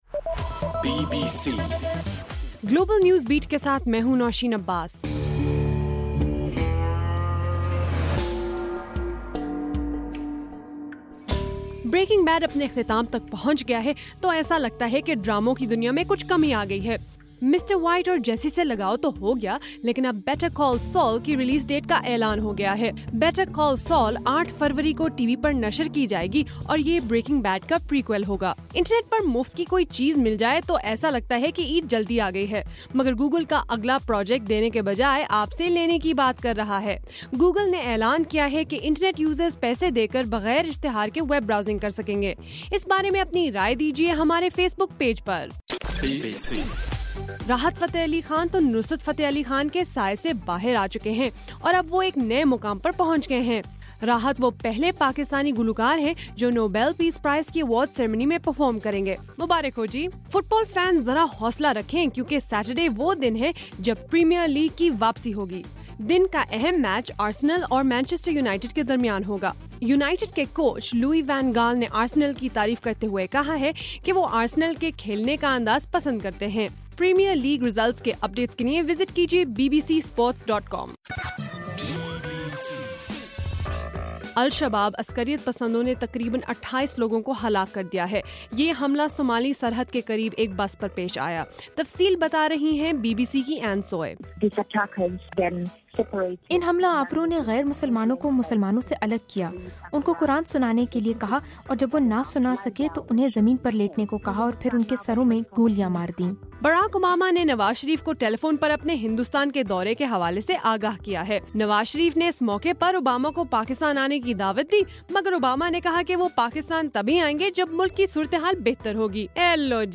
نومبر 22: رات 10 بجے کا گلوبل نیوز بیٹ بُلیٹن